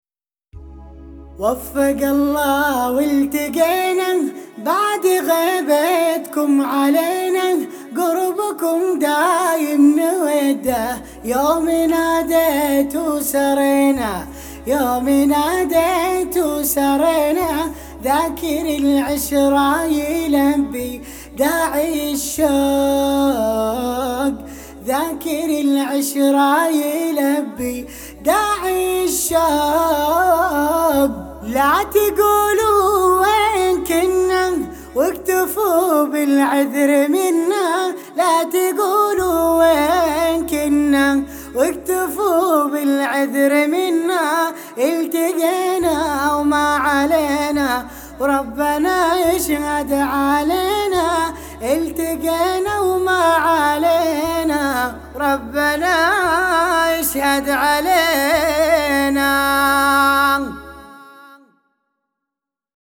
كرد